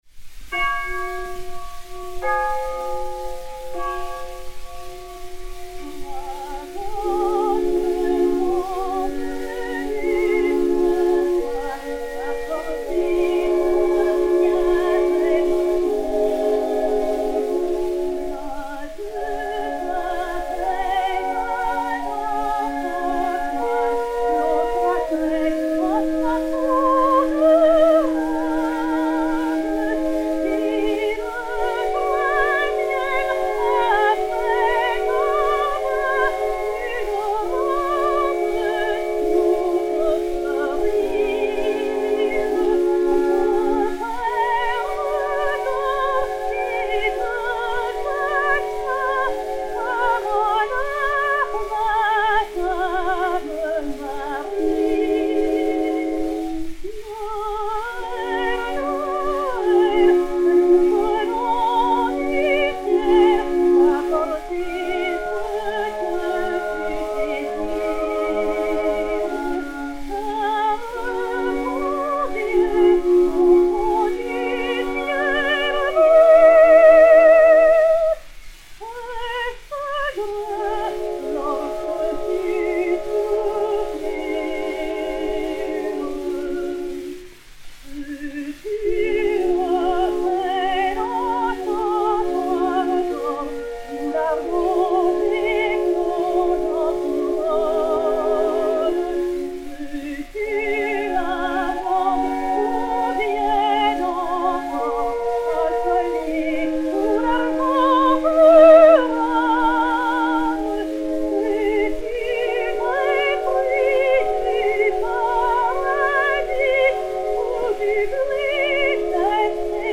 Berthe Auguez de Montalant, Choeurs, Orgue et Cloches
Disque Pour Gramophone 33648, mat. 5111h, enr. à Paris en 1907